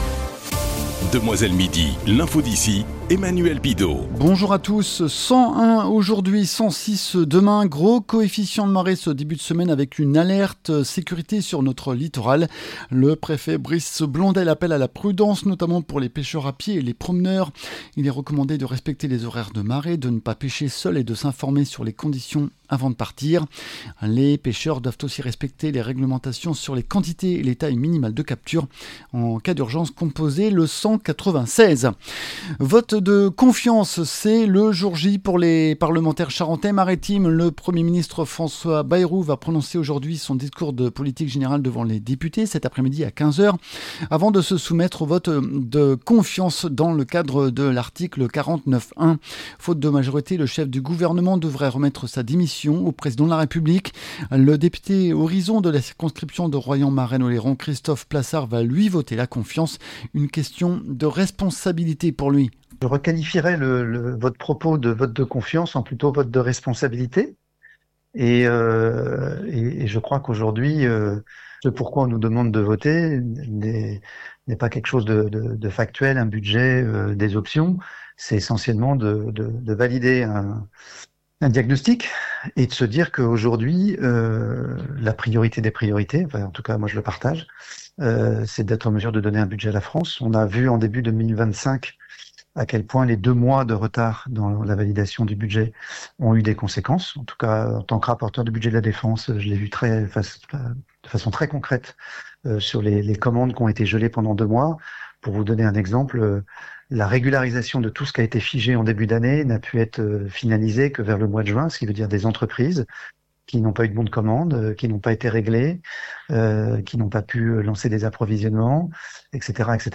Le journal de ce Lundi 08 Septembre midi